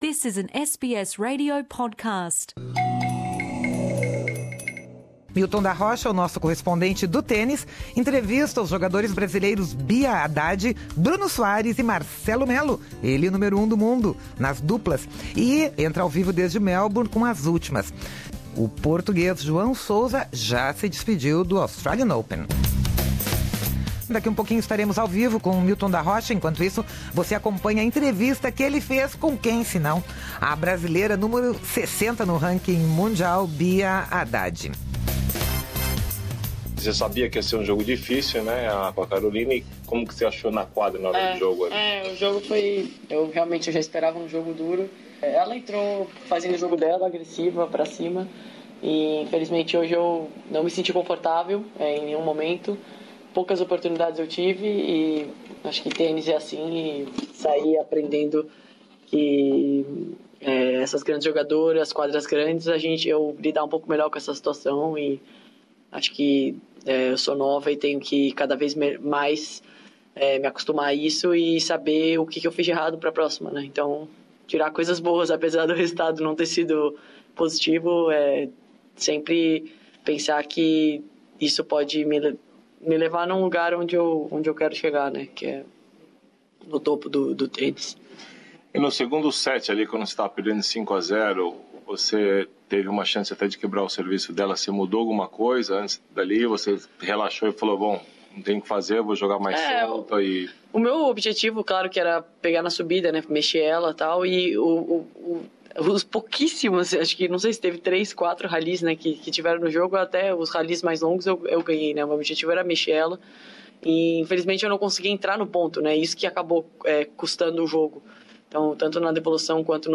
Entrevistas com os tenistas Beatriz Haddad, Bruno Soares e Marcelo Melo